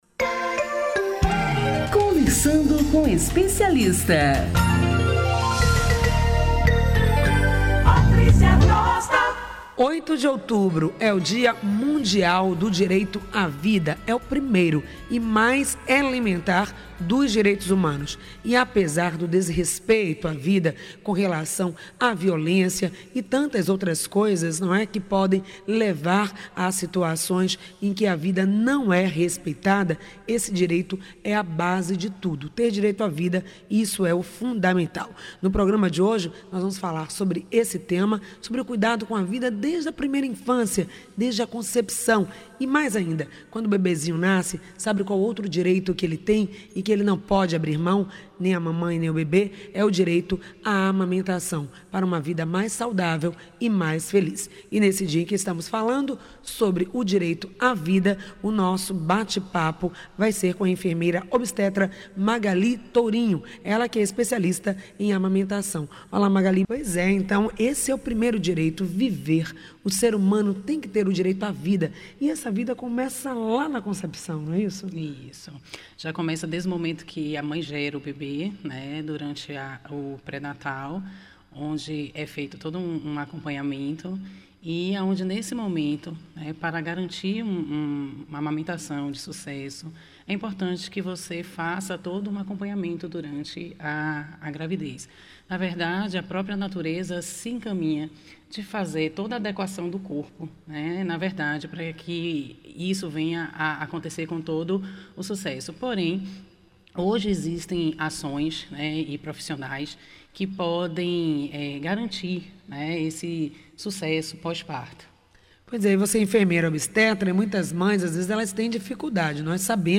Quais são as dificuldades reais que ambos enfrentam nesta bela e saudável ação? Quais as técnicas disponíveis para ajudar a superarem os desafios? É o que você vai saber neste bate papo com a enfermeira obstetra